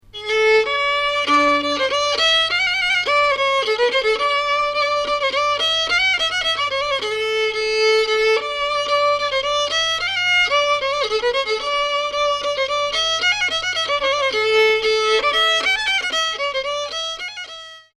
, Violon et chant Extrait audio (MP3)